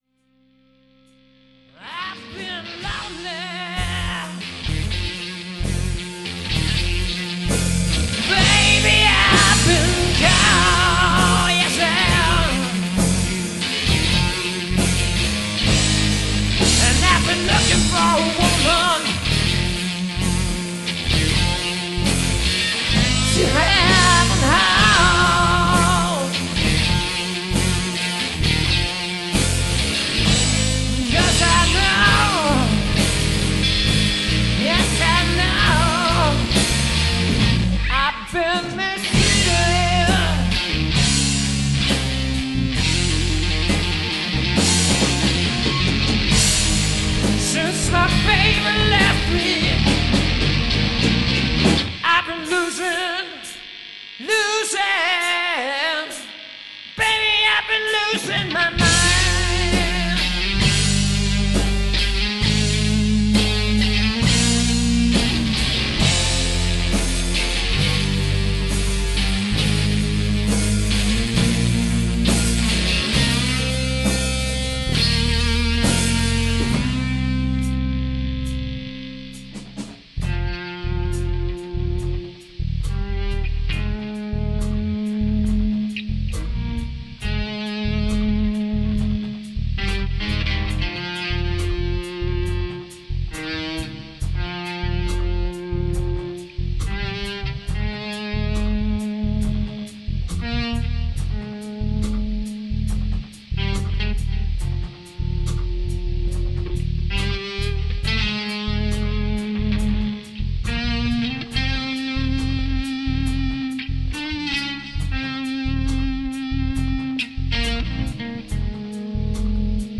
rock and hard rock
vocals, guitar
bass, backing vocals
drums, percussion
Mitschnitte aus dem Proberaum